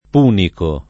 [ p 2 niko ]